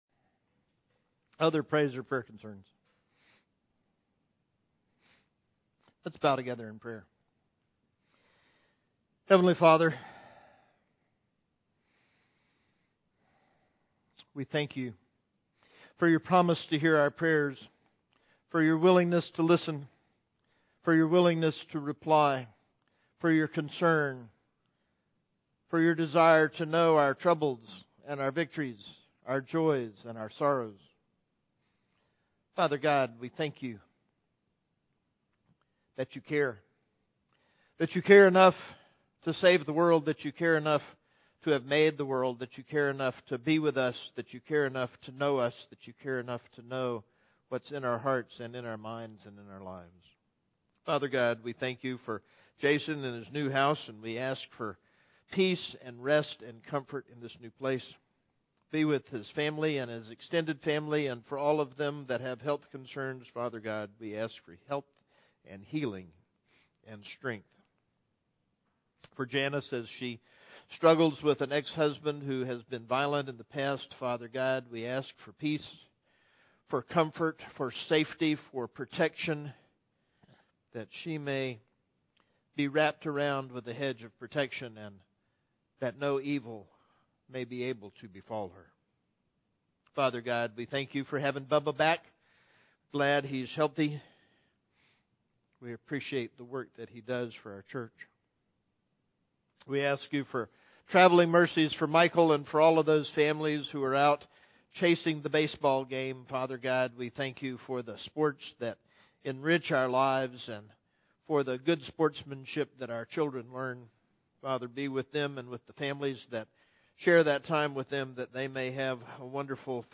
Pastoral Prayer